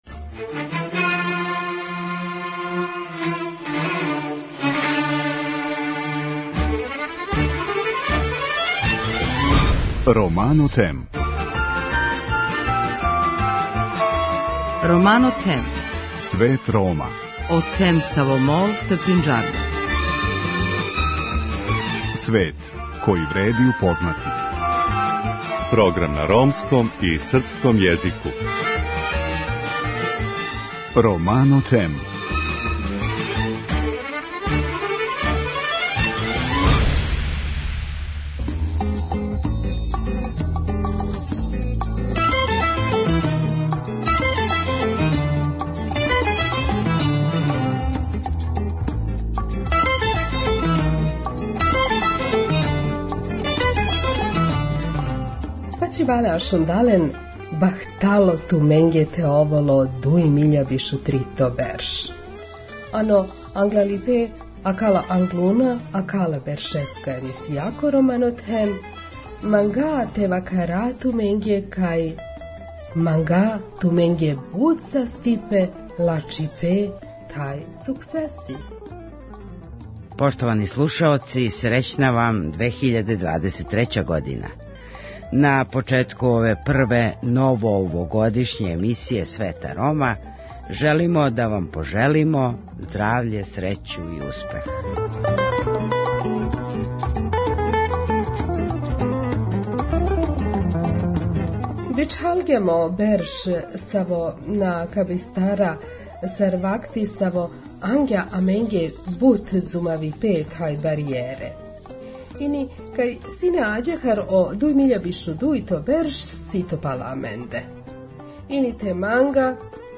У првој овогодишњој емисији желимо да вам се захвалимо на томе што сте нас пратили и подржавали прошле, али и свих претходних година. У данашњем издању, док се одмарате од дочека Нове, слушаћете честитке и новогодишње жеље пријатеља Света Рома.